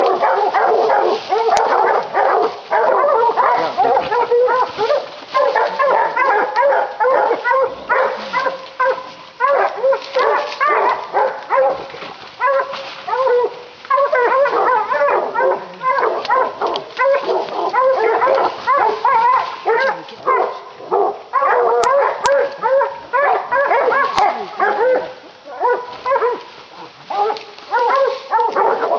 Dogs baying hog ringtone ringtone free download
Animals sounds